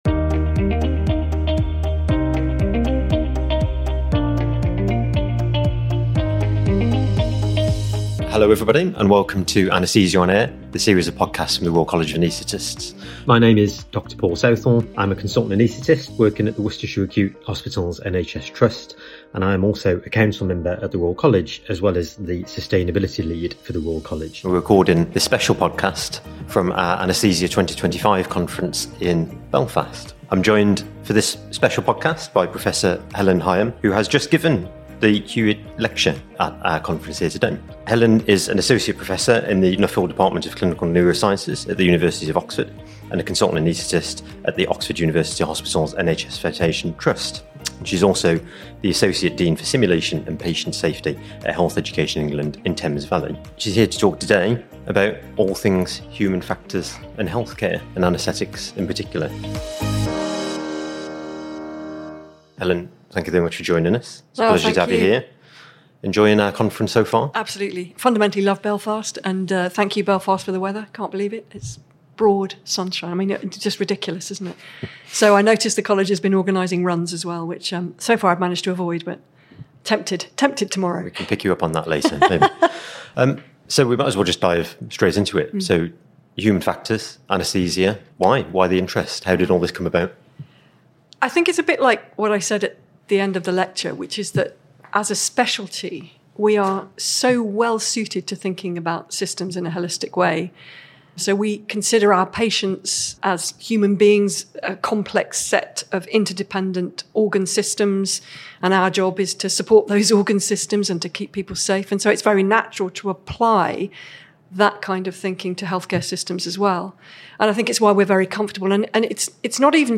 recorded at our Anaesthesia 2025 conference